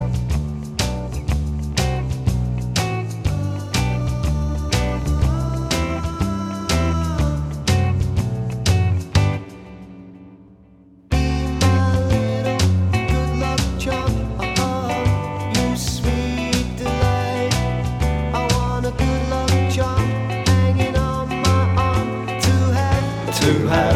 Minus Guitars Pop (1960s) 2:32 Buy £1.50